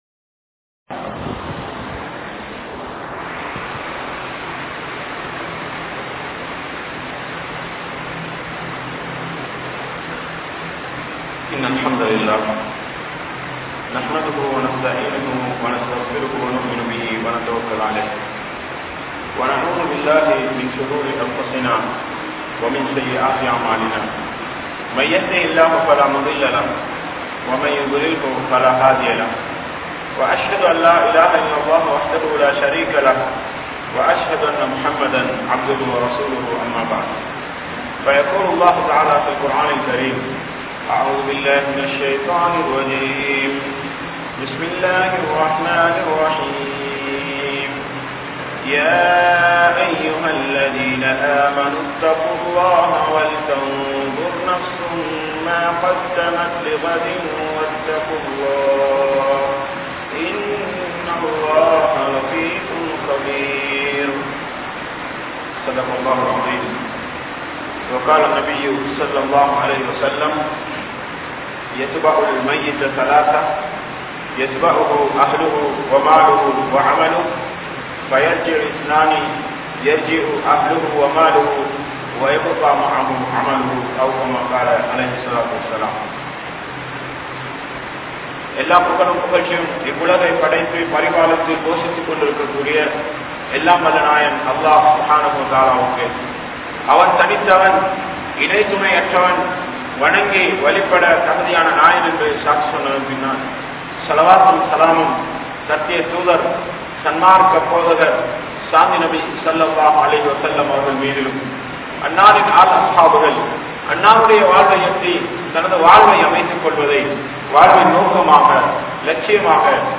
After Ramalan | Audio Bayans | All Ceylon Muslim Youth Community | Addalaichenai